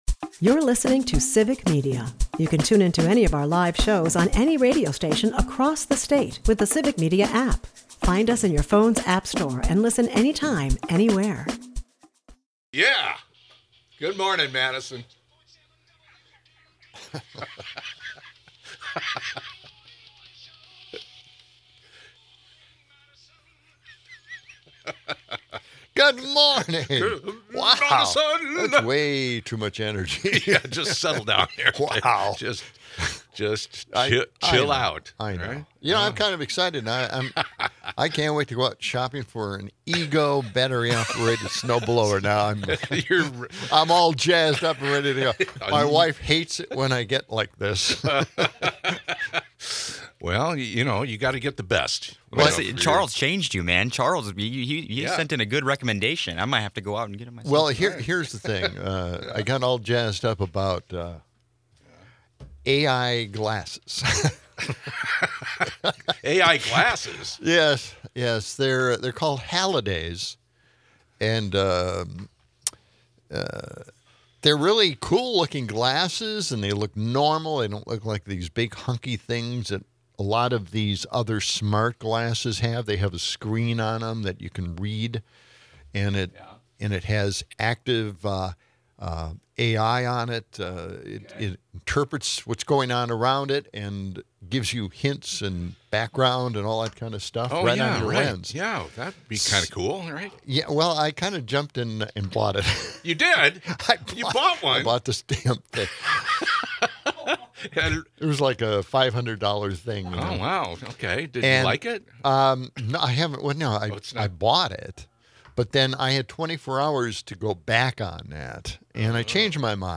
In a whirlwind of tech talk and political banter, our hosts dive into their latest obsessions: AI glasses and eco-friendly snowblowers. The excitement is palpable until the AI glasses purchase goes awry, sparking a debate on tech spontaneity. The show shifts gears to a text-to-win contest, enticing listeners with cash and grand prizes.